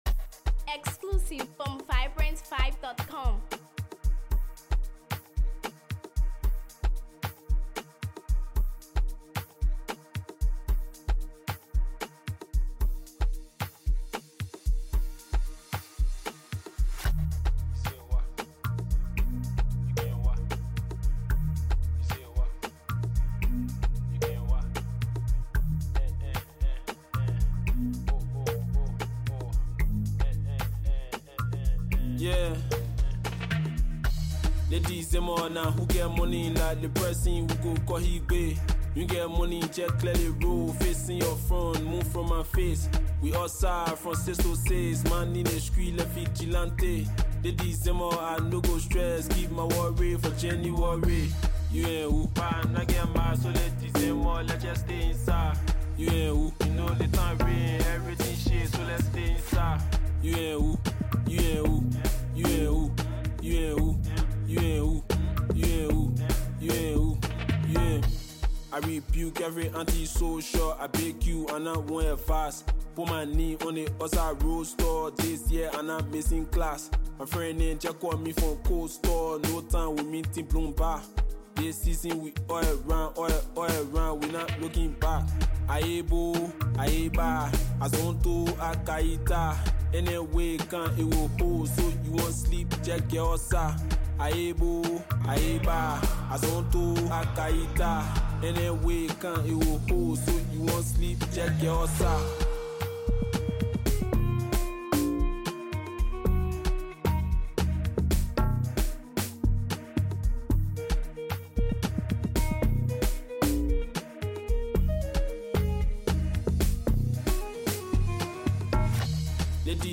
from track 1 to track 4 is a hard tempo!